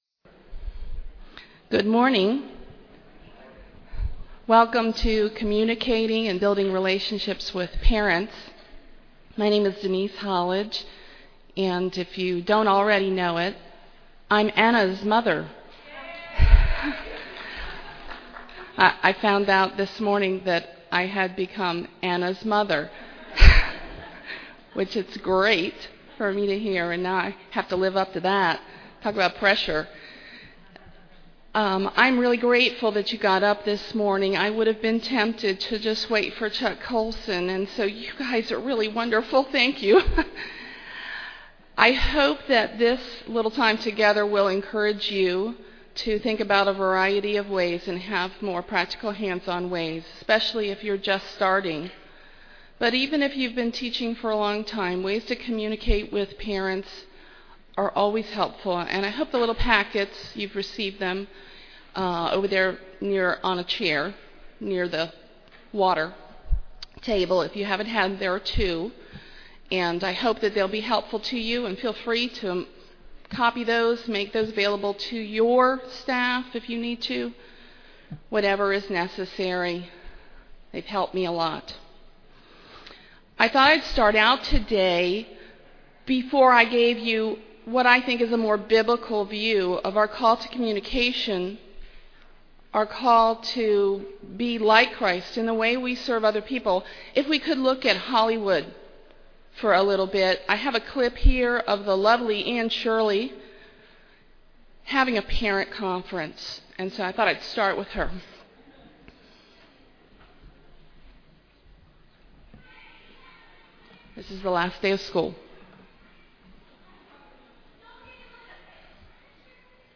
2009 Workshop Talk | 0:57:52 | All Grade Levels, Marketing & Growth